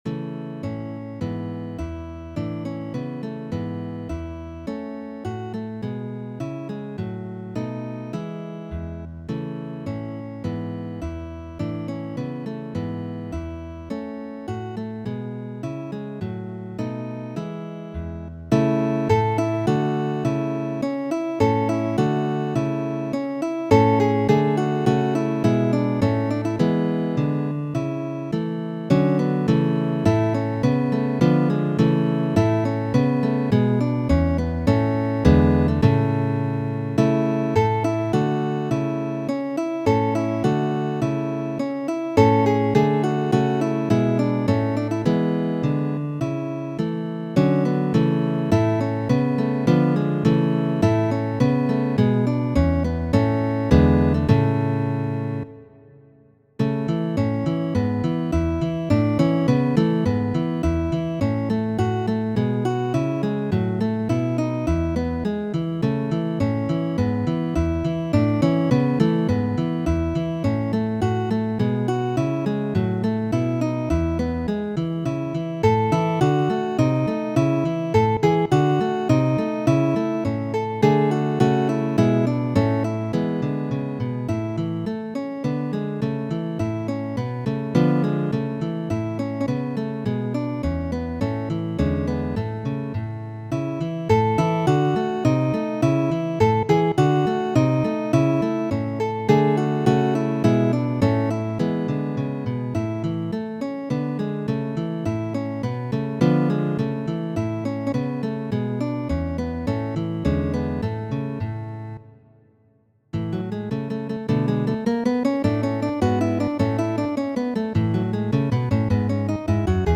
Variaĵoj por gitaro pri La harmonia forĝisto de Georgo Haendel, verkitaj de Mario Ĝuljani.